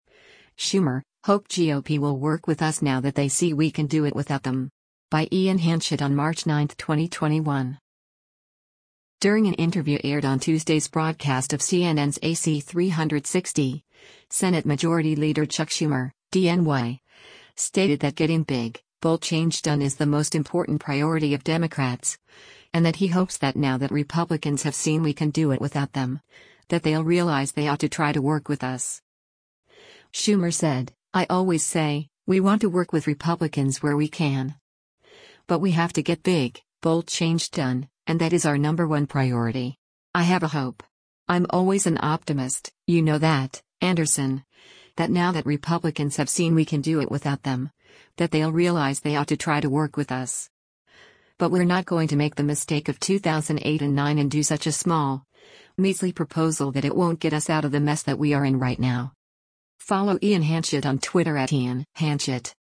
During an interview aired on Tuesday’s broadcast of CNN’s “AC360,” Senate Majority Leader Chuck Schumer (D-NY) stated that getting “big, bold change done” is the most important priority of Democrats, and that he hopes “that now that Republicans have seen we can do it without them, that they’ll realize they ought to try to work with us.”